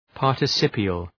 Προφορά
{,pɑ:rtı’sıpıəl}